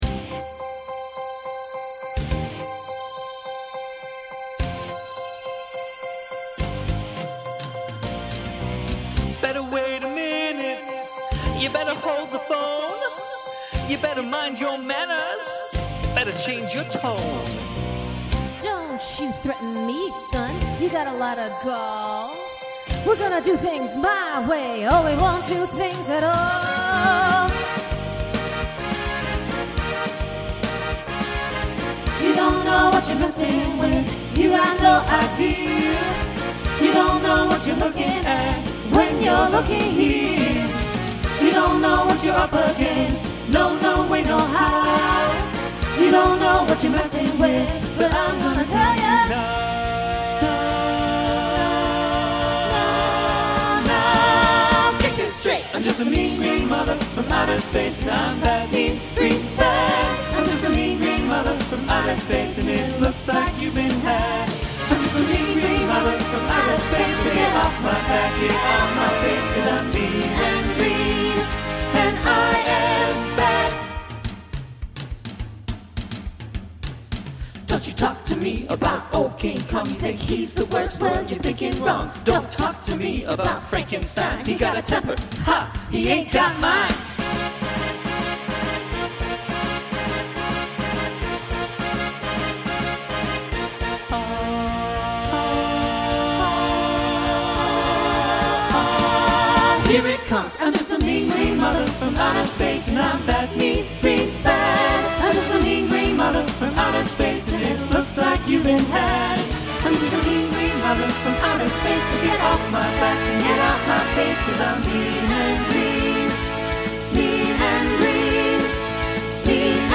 Voicing SATB Instrumental combo
Broadway/Film , Rock